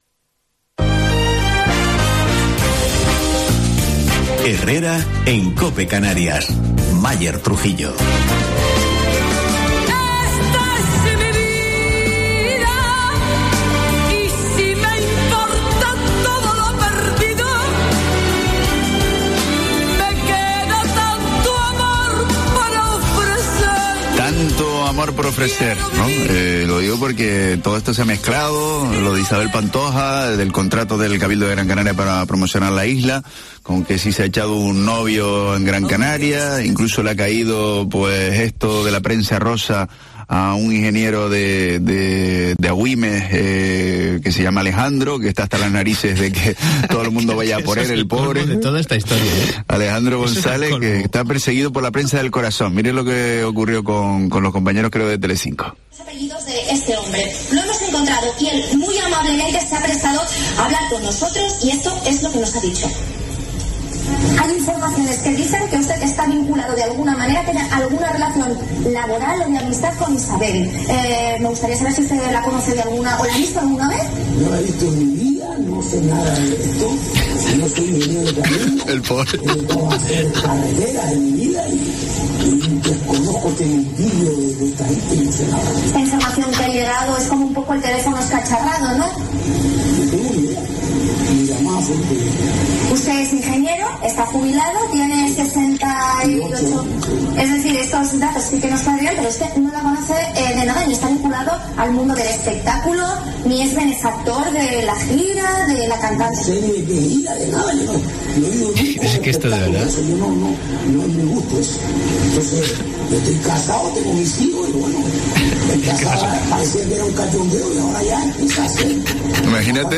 Entrevista en Herrera en Cope Canarias a Miguel Jorge, portavoz del PP en el Cabildo de Gran Canaria